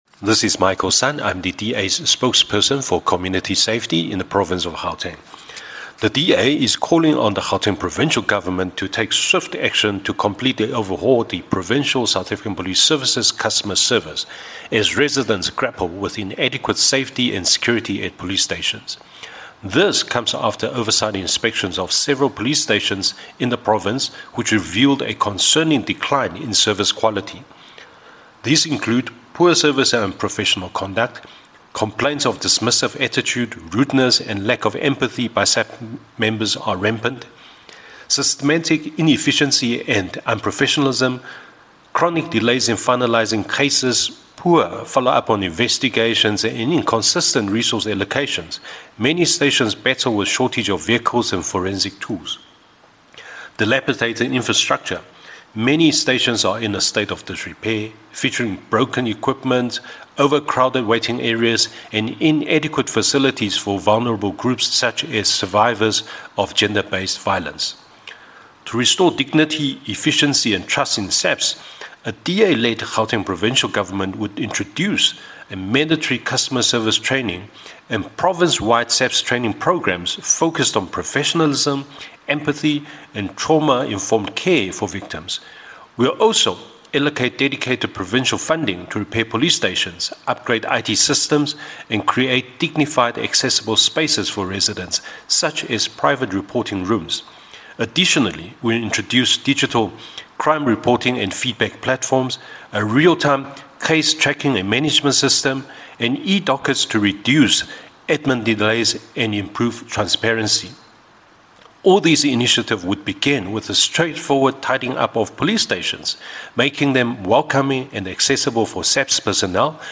English soundbite by Michael Sun MPL.